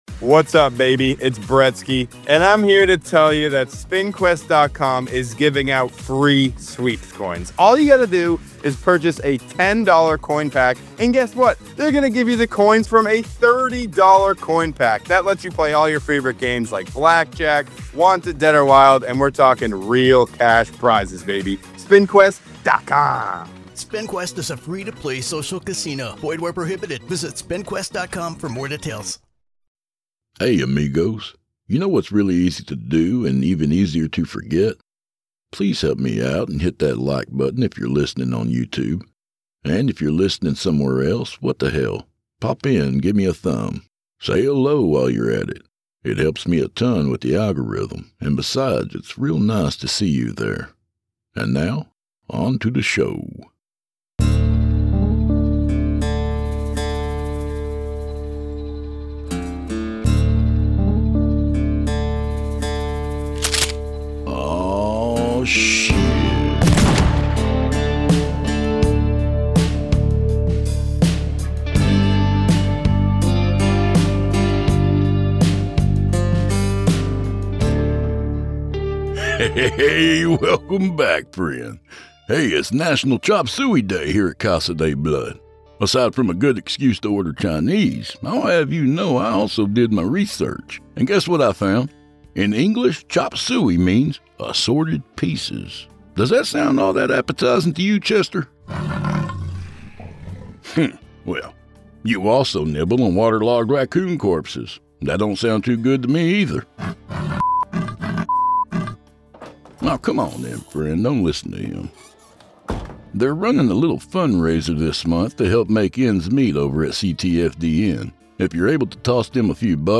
a crackling storm outside